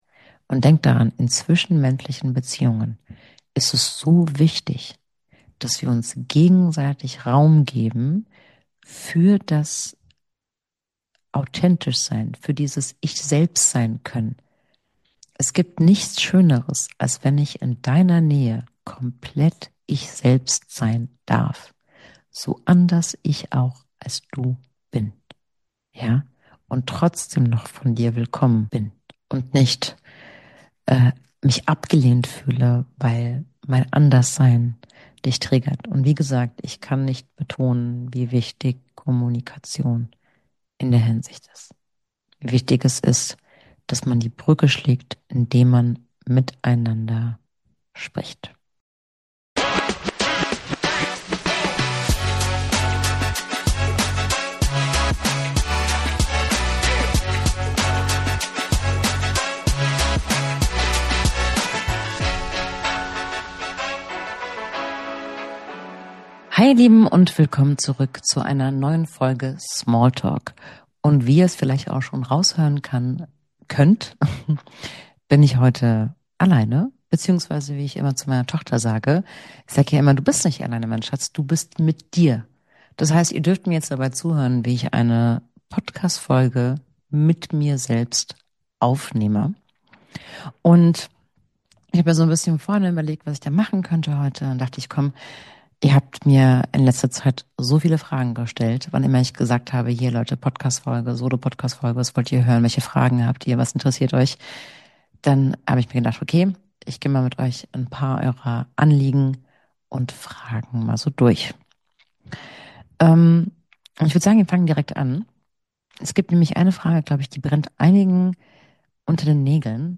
Solo-Folge